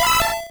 Cri d'Hypocéan dans Pokémon Rouge et Bleu.